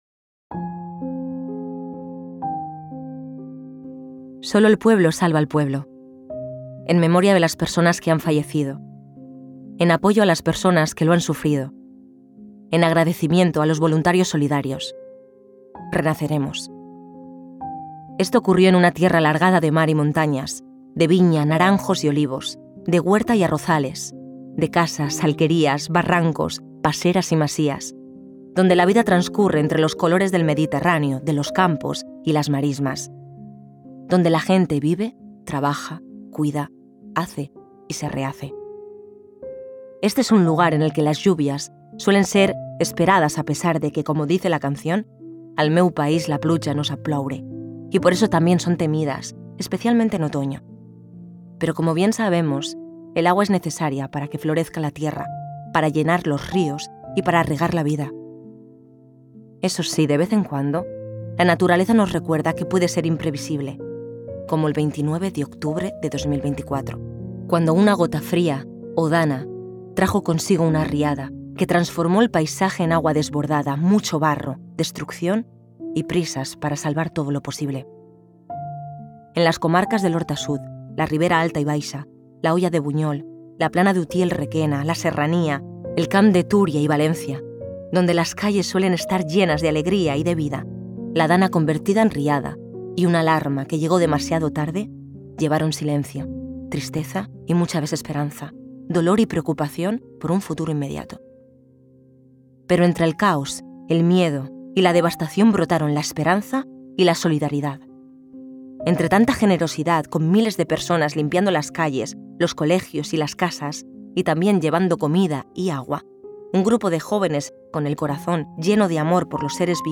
Audiolibro: Olivia y Mel - La palabra rescatada
XDANA_CASTELLANO_MUSICA.mp3